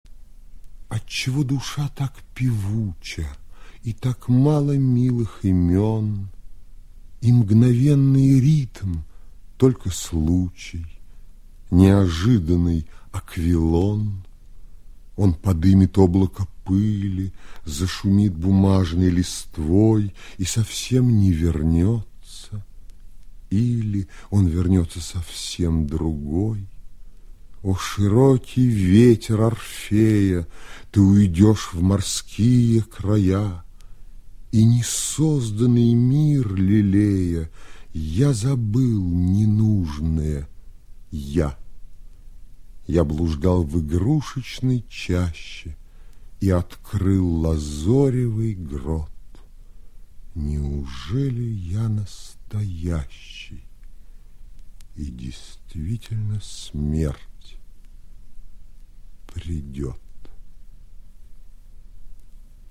osip-mandelshtam-otchego-dusha-tak-pevucha-1911-chitaet-mihail-kozakov